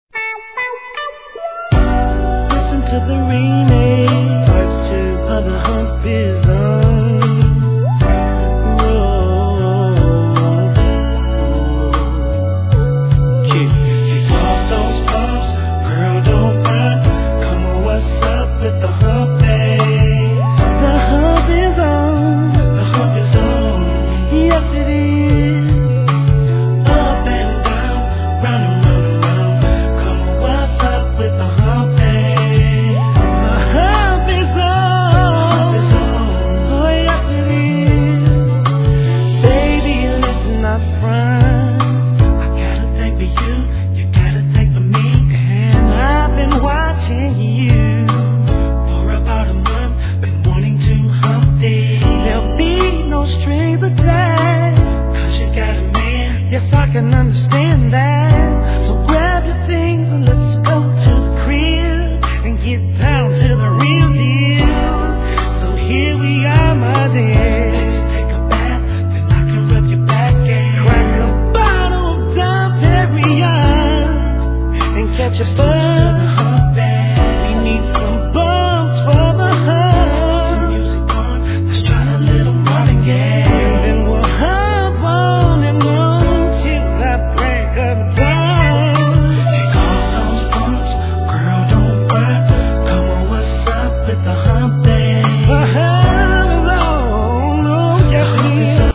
HIP HOP/R&B
確かなヴォーカルで聴かせるメロ〜R&B♪